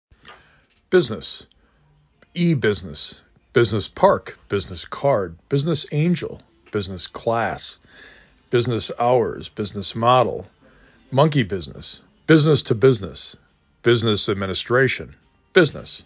8 Letters, 2 Syllable
b i z n ə s
b i z n i s